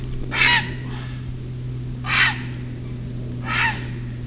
Caratteristico il suo rauco grido di
richiamo (185 KB) emesso prevalentemente mentre vola e che può essere udito a grande distanza.
aironecenerino.wav